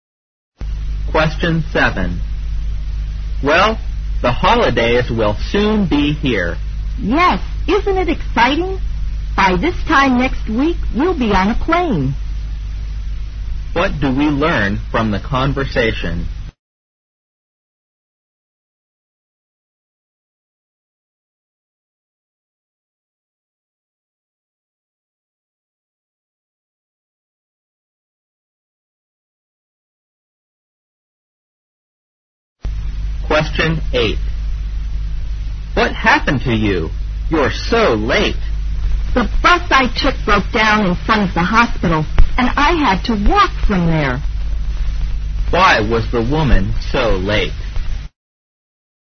在线英语听力室157的听力文件下载,英语四级听力-短对话-在线英语听力室